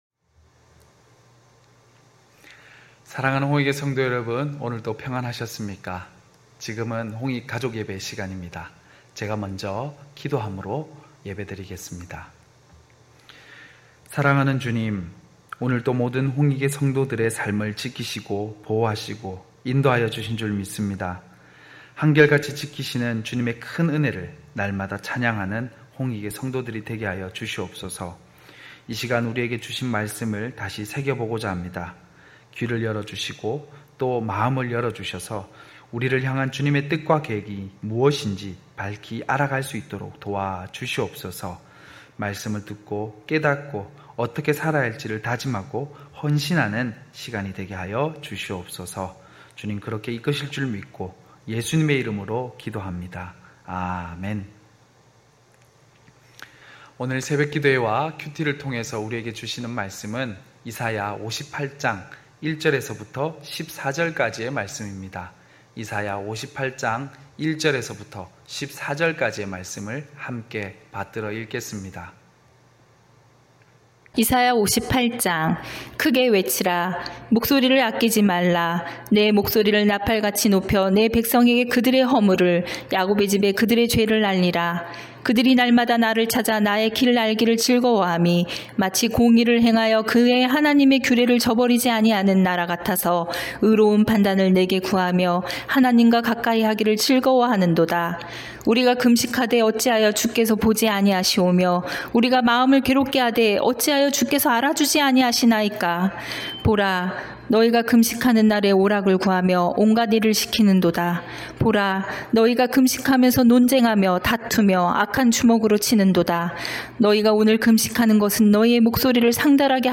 9시홍익가족예배(8월7일).mp3